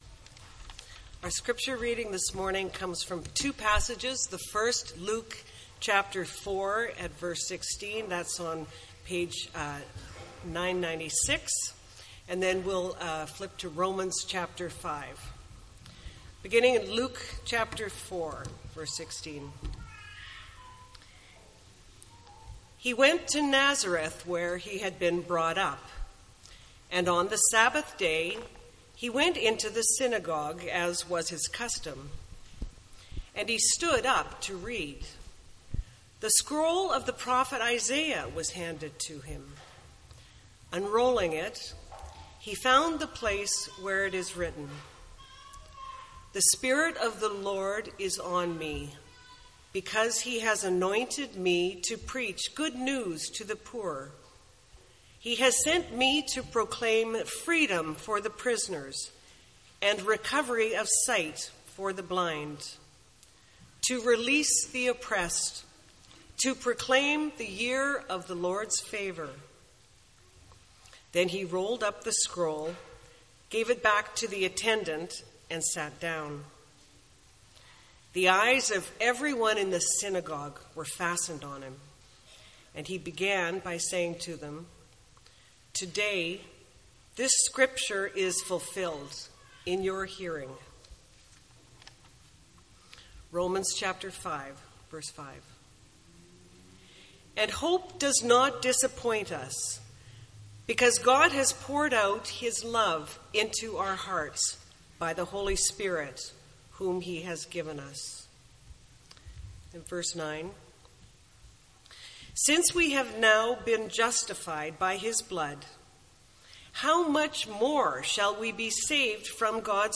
MP3 File Size: 16.3 MB Listen to Sermon: Download/Play Sermon MP3